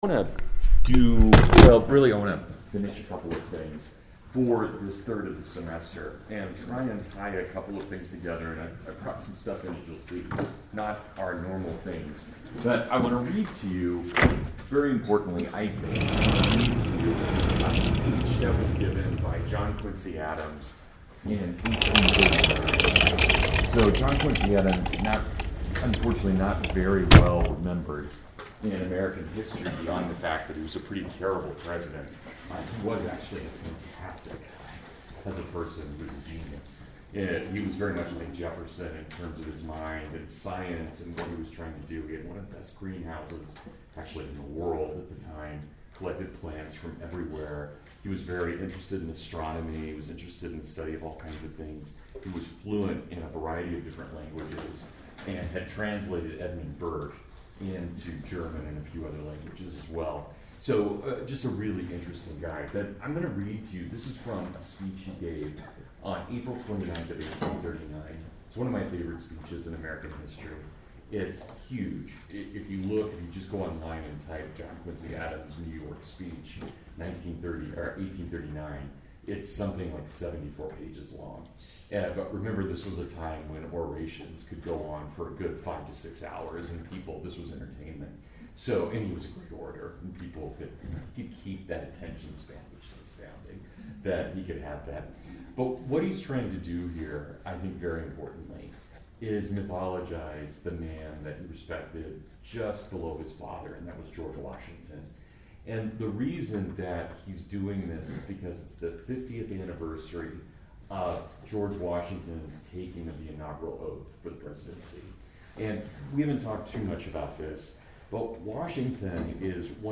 George Washington, Roman Demigod Reborn (Full Lecture)